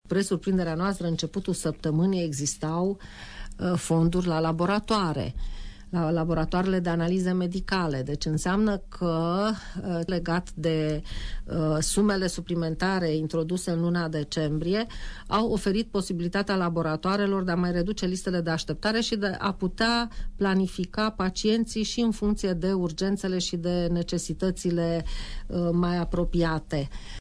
Declarația a fost făcută de preşedintele CJAS Mureş, Rodica Biro, în cadrul emisiunii Părerea ta de la RTM: